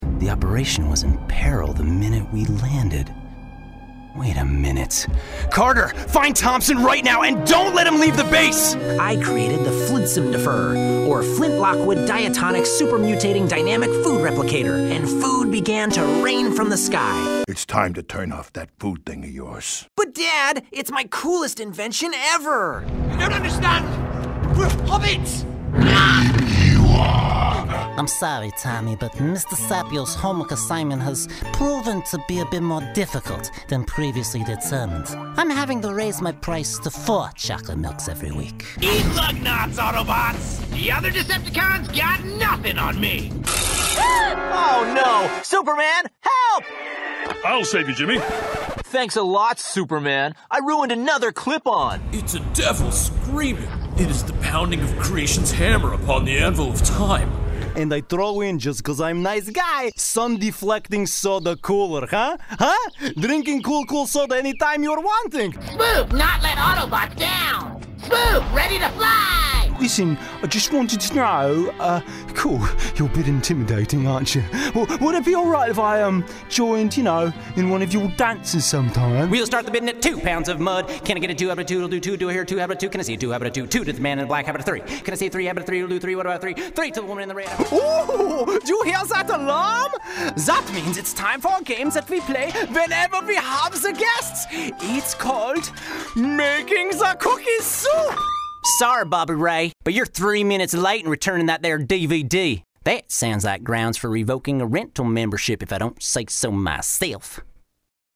ANIMATION VO Demo (click here for mp3)
Featuring voices for Transformers, Lord of the Rings,, MadTV, Power Rangers, Cloudy with a Chance of Meatballs, and more.